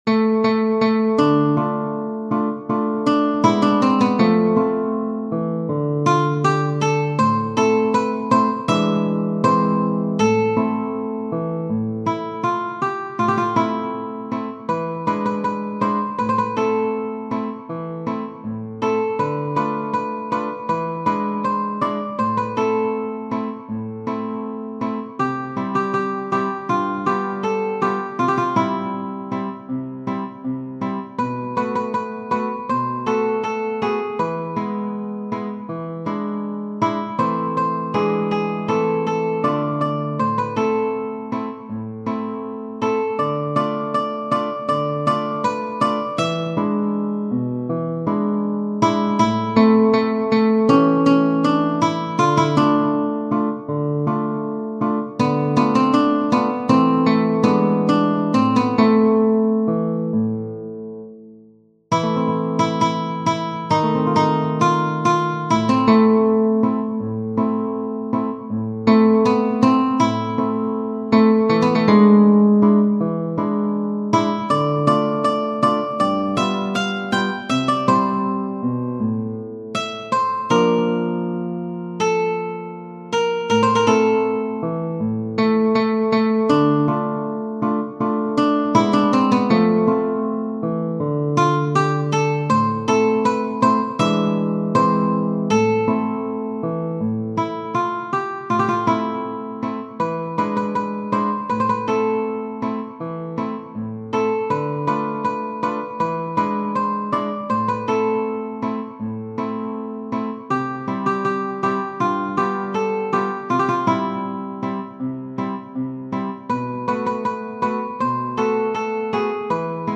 Buongiovanni, F. Genere: Napoletane Testodi L. Bovio, musica di F. Buongiovanni.